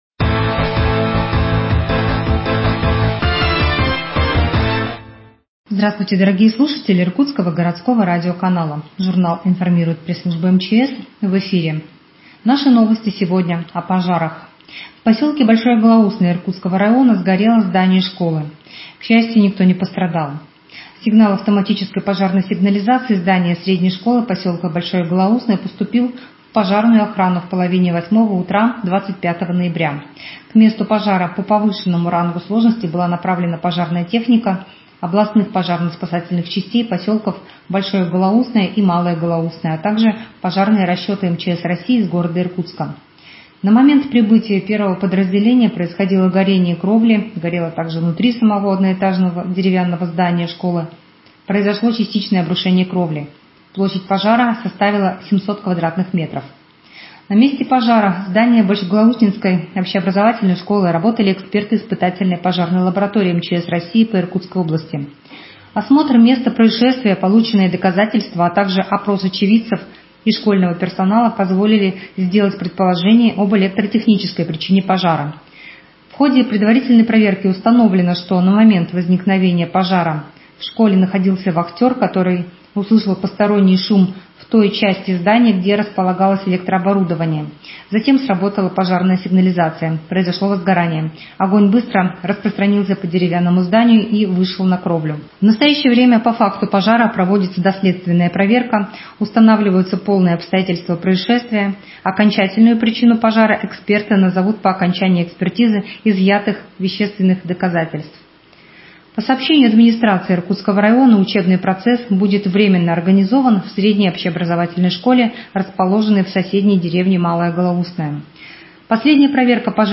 Еженедельная пятничная рубрика ГУ МЧС России по Иркутской области «Радиожурнал МЧС».